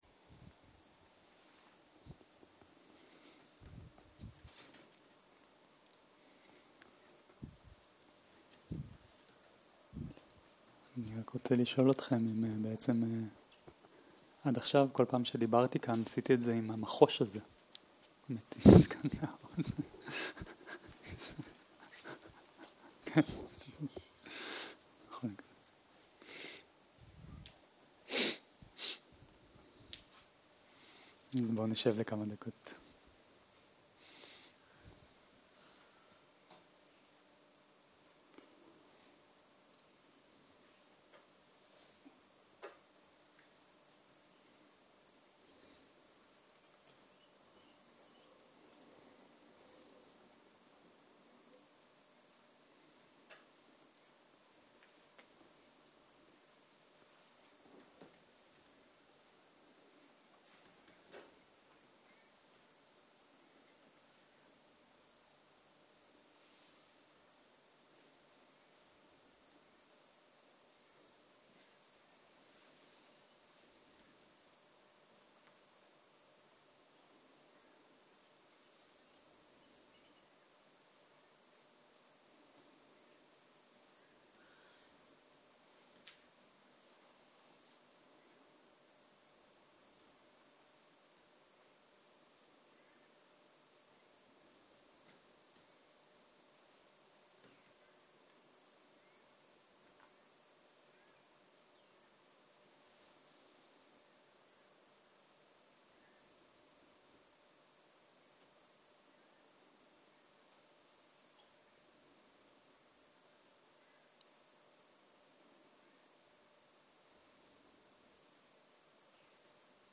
14.02.2023 - יום 7 - בוקר - הנחיות מדיטציה - אניצ'ה, שינוי, ארעיות - הקלטה 10